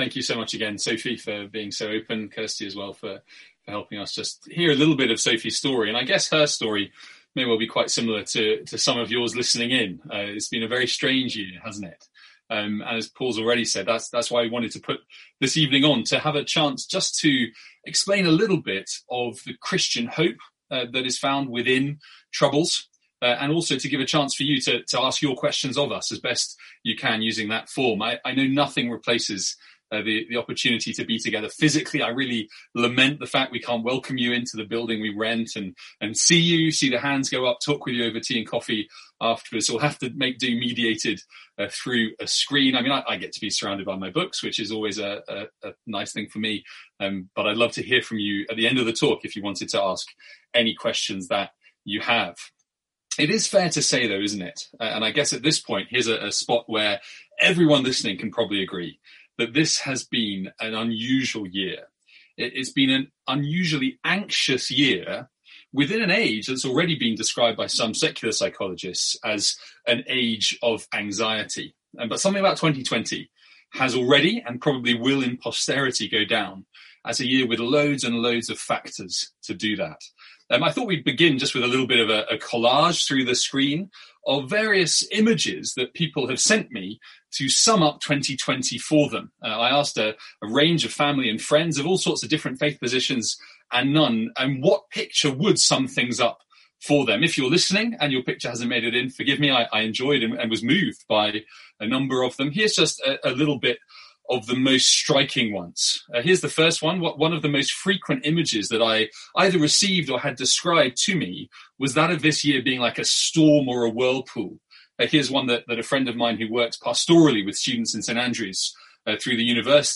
Sermons | St Andrews Free Church
A One Question Event.